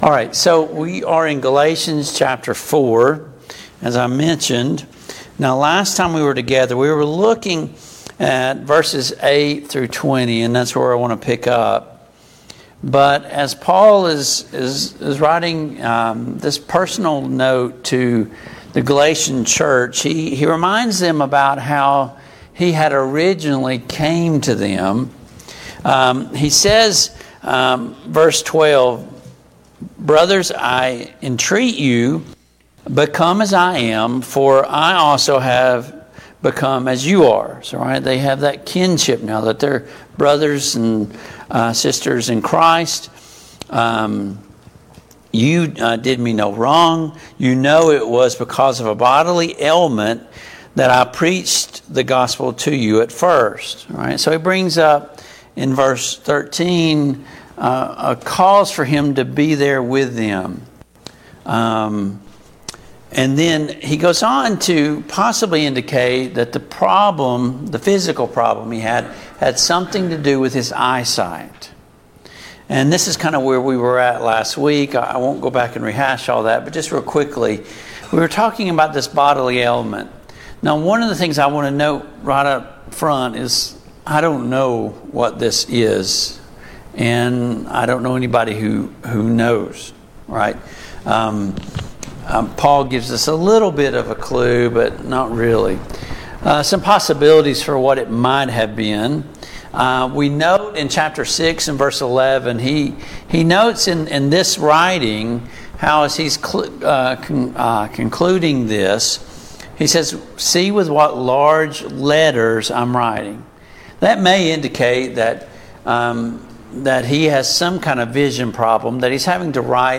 Passage: Galatians 4:12-31, Galatians 5:1-4 Service Type: Mid-Week Bible Study